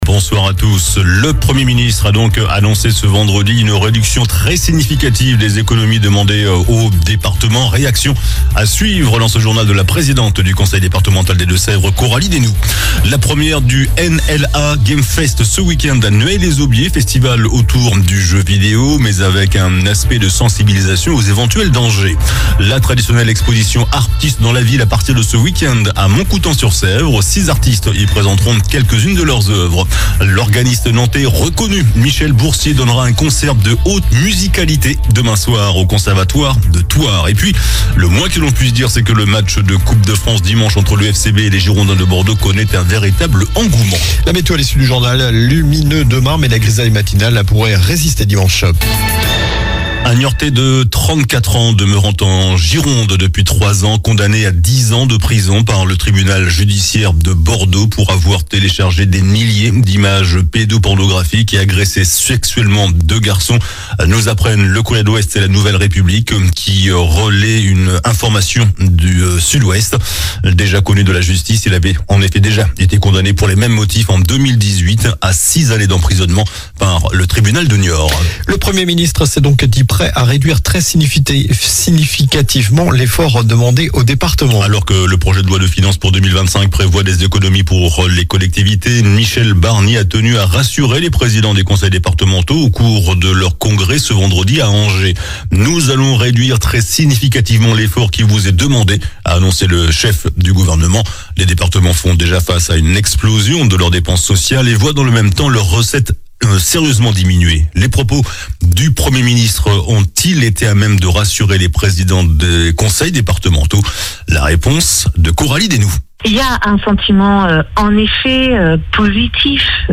JOURNAL DU VENDREDI 15 NOVEMBRE ( SOIR )
Le premier Ministre a annoncé ce vendredi une réduction "très significative" des économies demandée aux départements. Réaction de la présidente du conseil départemental des deux-sèvres Coralie Desnoues.